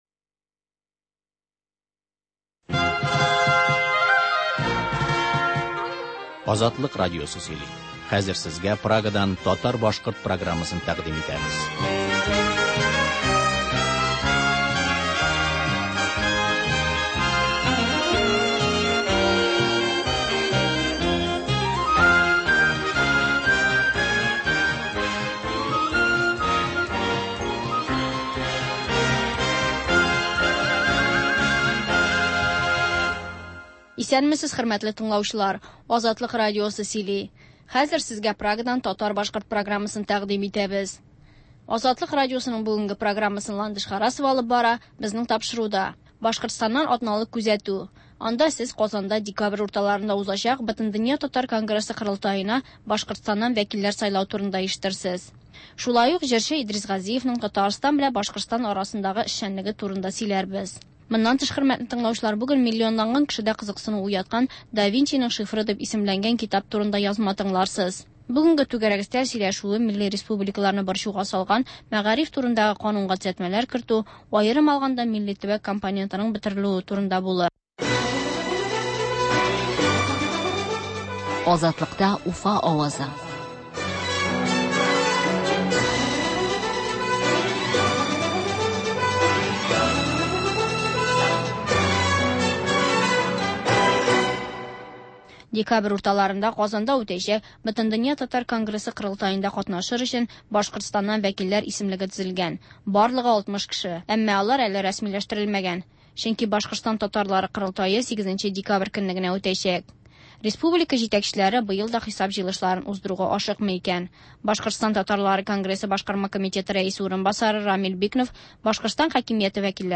сәгать тулы хәбәр - Башкортстаннан атналык күзәтү - түгәрәк өстәл артында сөйләшү